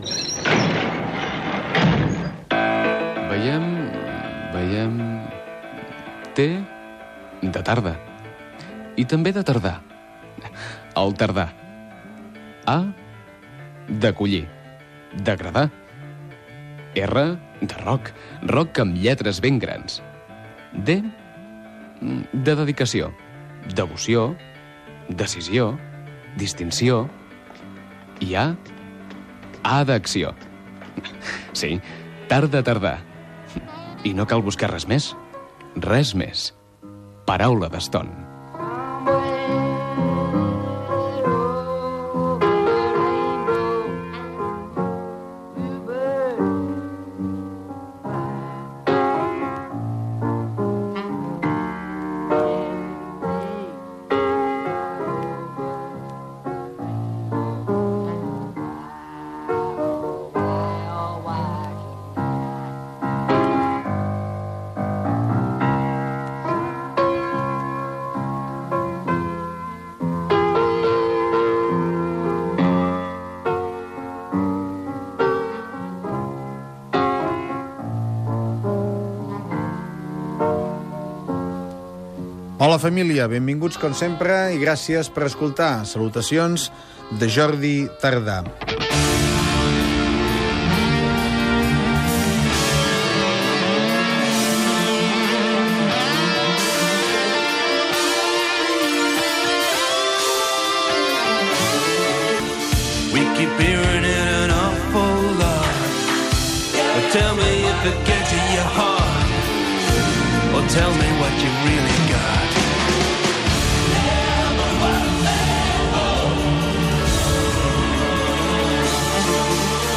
Careta del programa, presentació, temes musicals
Musical